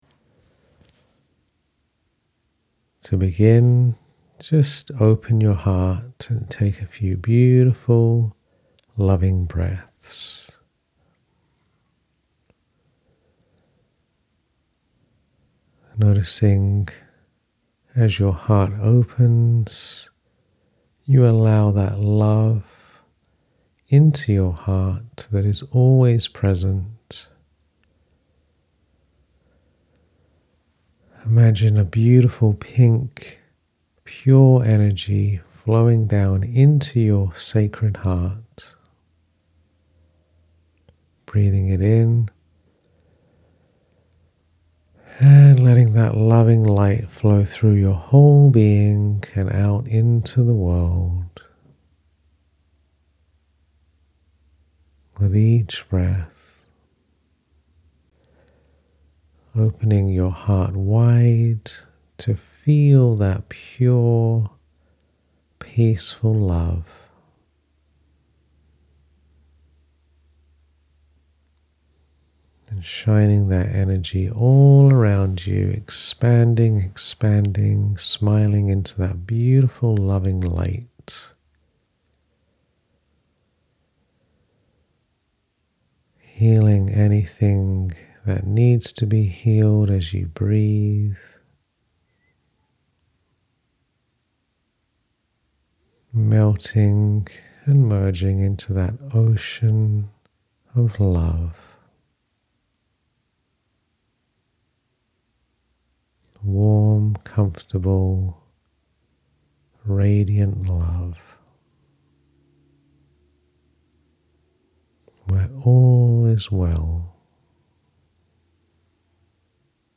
Shift from thinking to feeling and reconnect with your inner wisdom. This meditation guides you out of the mind and into the calm, loving intelligence of your heart—bringing peace, clarity, and emotional alignment. Ideal for grounding yourself, making heart-led decisions, or simply returning to love.